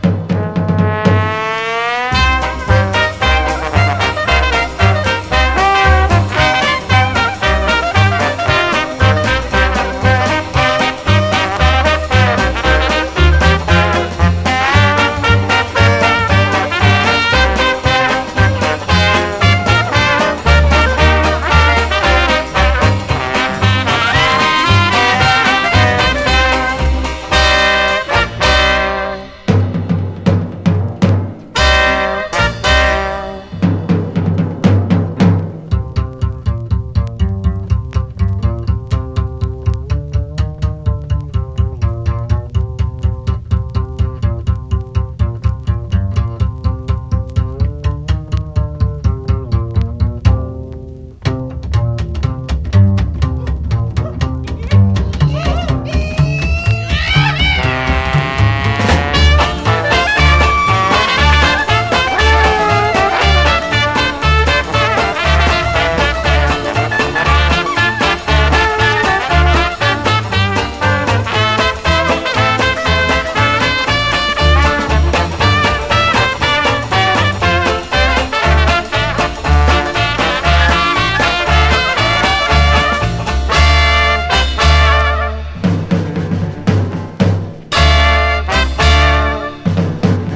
JAZZ / MAIN STREAM / DIXIELAND JAZZ / OLDIES / JAZZ & JIVE
日本のメロディーをゴキゲンなディキシーランド/トラッド・ジャズ・カヴァー！
日本の歌謡曲・童謡をゴキゲンにスウィング・カヴァー！